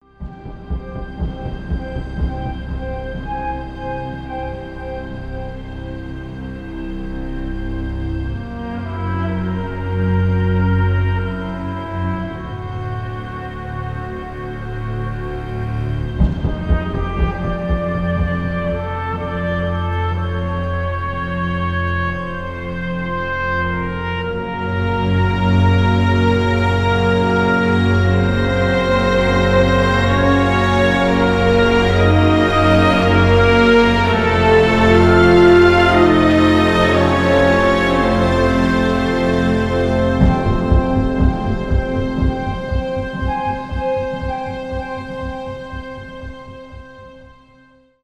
инструментальные
тревога , без слов